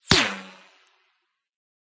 whine_5.ogg